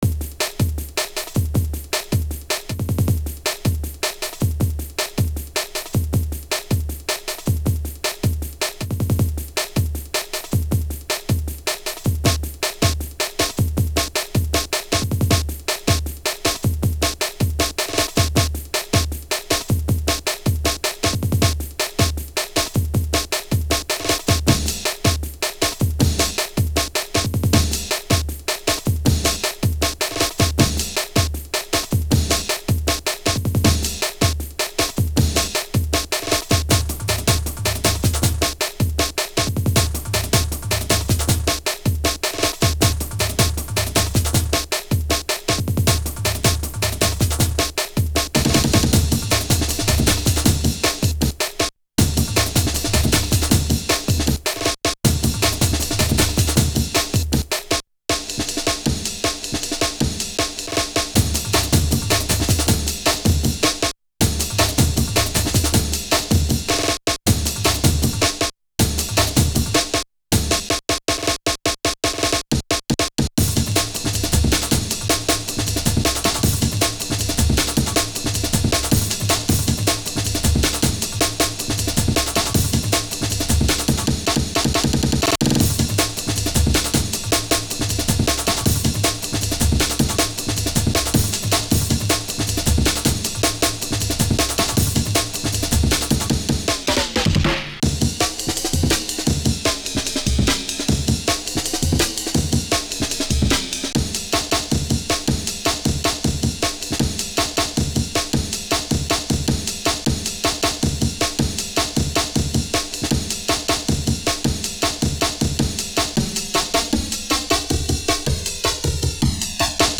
Index of /90_sSampleCDs/Jungle_Frenzy_1/_01 Programmed Breaks